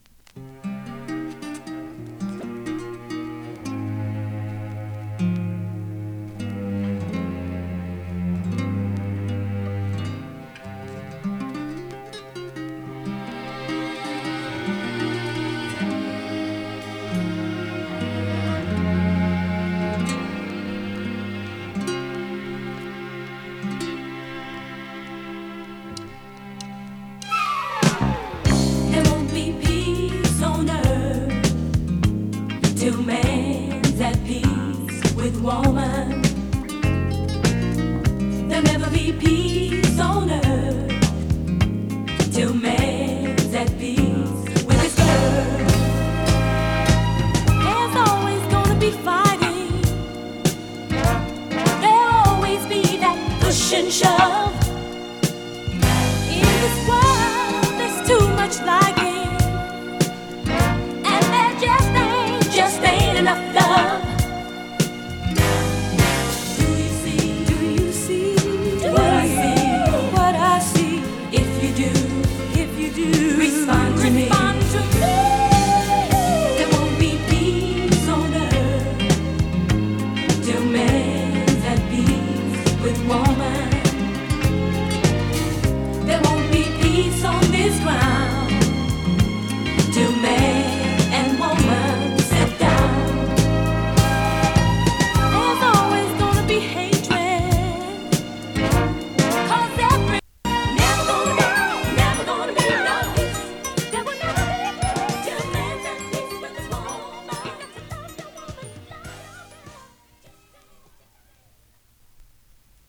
フュージョン ソウル